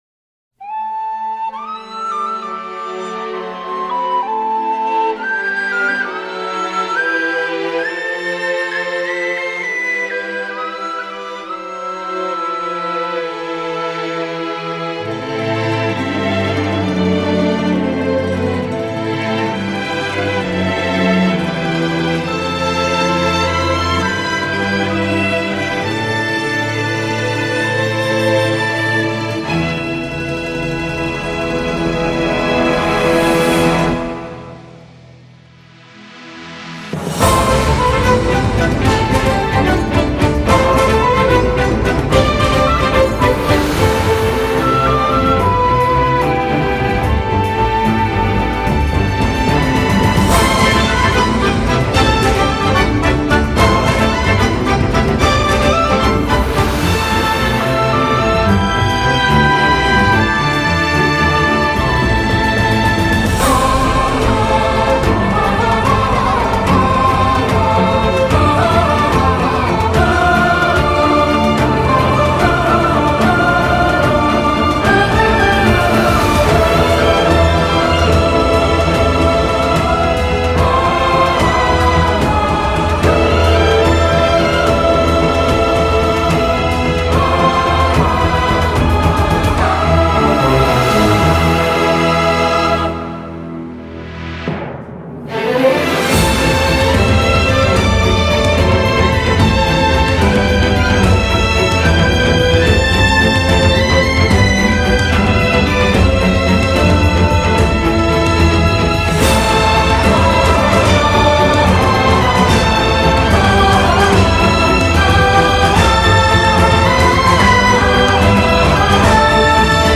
موسیقی اینسترومنتال
موسیقی بی کلام
Anime OST